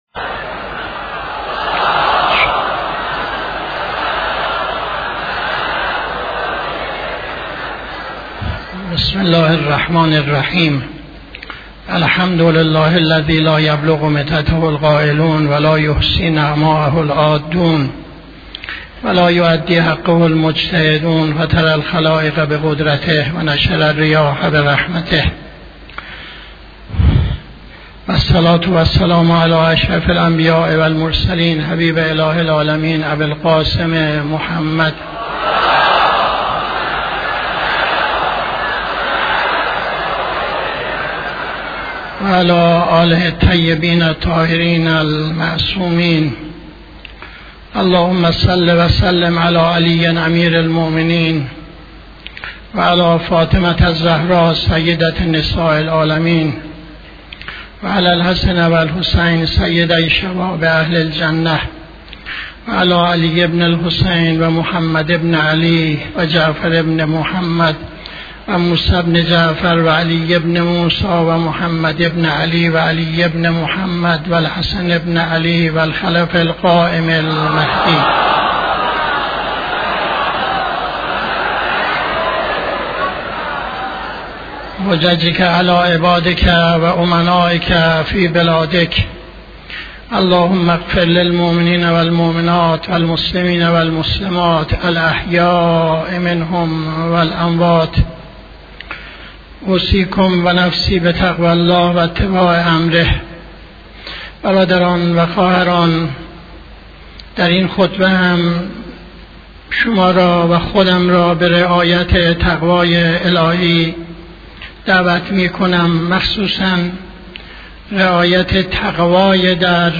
خطبه دوم نماز جمعه 05-12-79